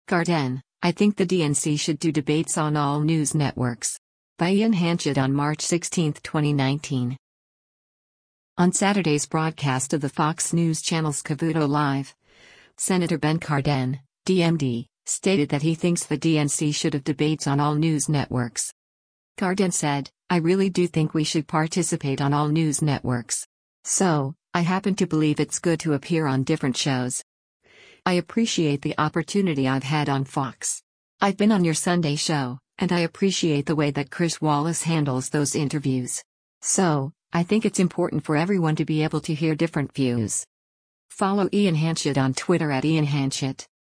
On Saturday’s broadcast of the Fox News Channel’s “Cavuto Live,” Senator Ben Cardin (D-MD) stated that he thinks the DNC should have debates “on all news networks.”